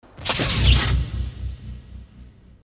В играх, фильмах, и еще бог знает где его слышал, удалось выдернуть в более или менее чистом варианте.